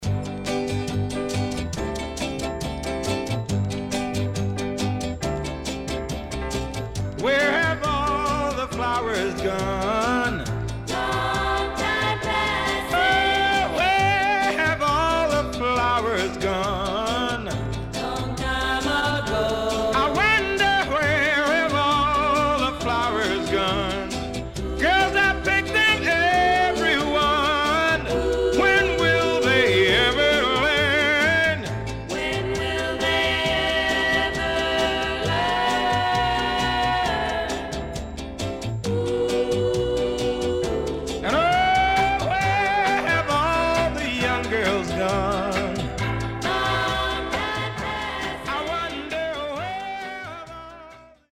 HOME > SOUL / OTHERS
64年Great Vocal.W-Side Good.724
SIDE A:盤質は良好です。